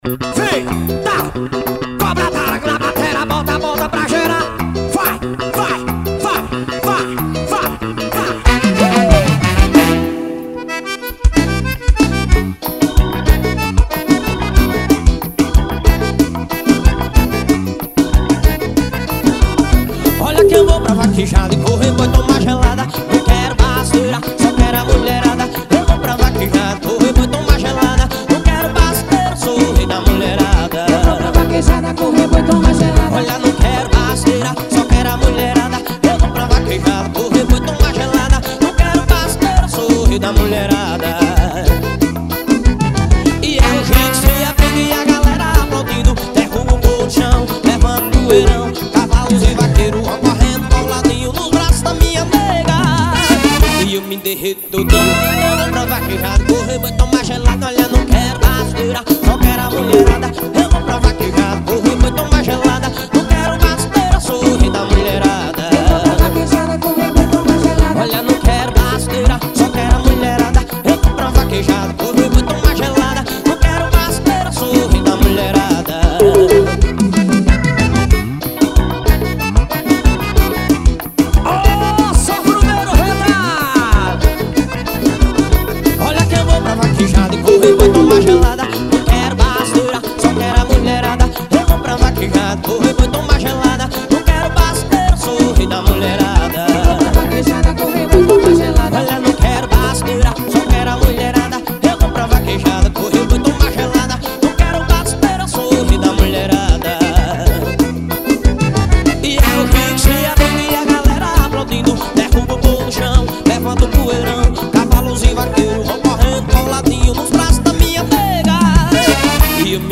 Ao Vivo em Capim Grosso BA.